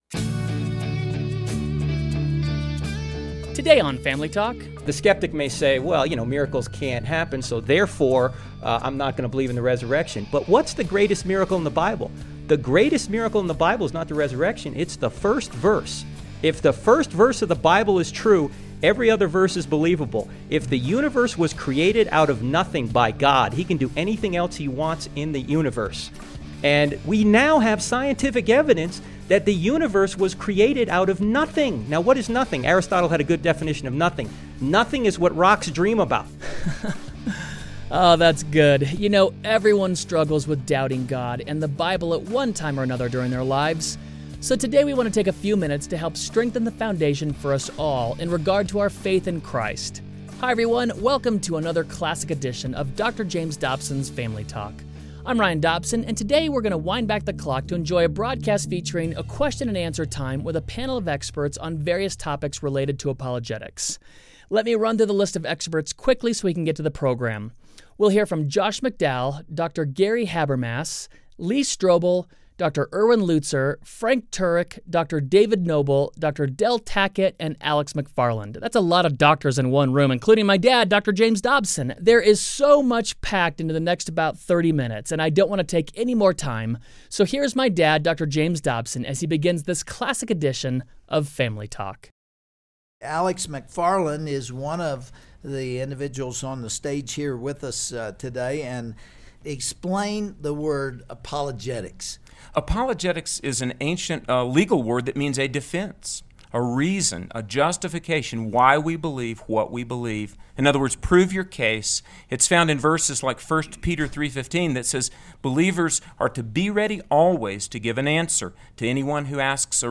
On the next edition of Family Talk, Dr. James Dobson interviews a panel of worldview experts on the topic of Christianity and the next generation.